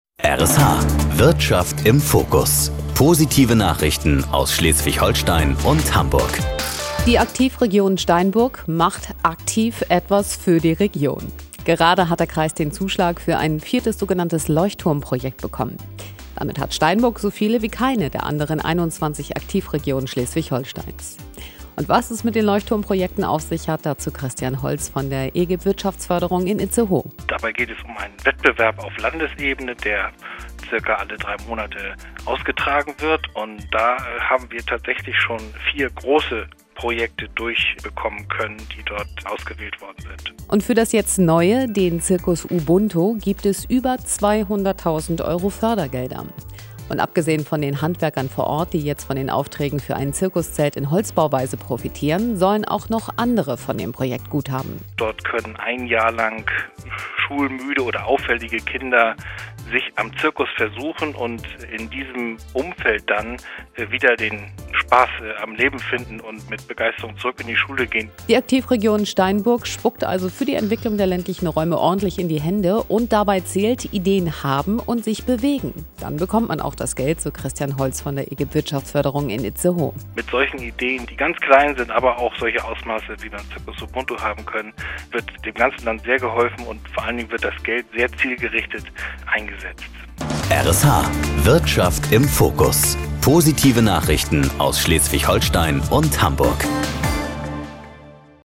News - R.SH Radiointerview